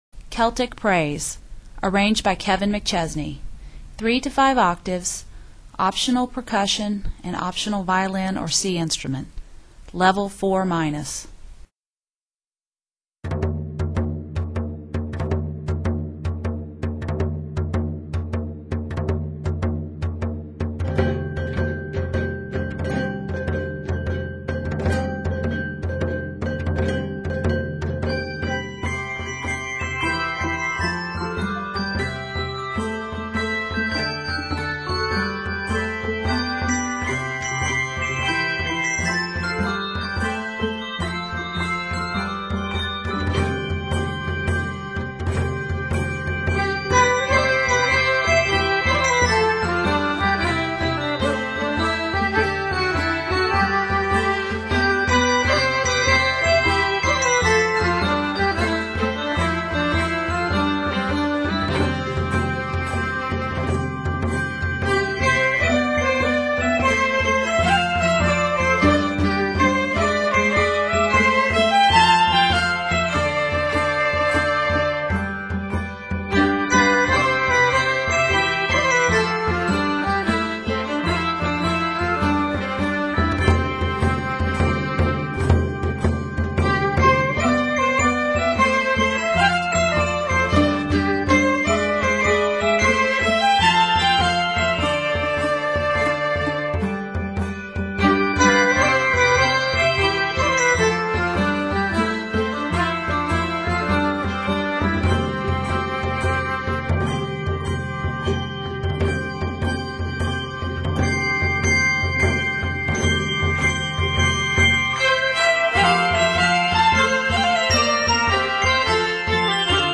This strong, lively tune
for handbells, percussion, and violin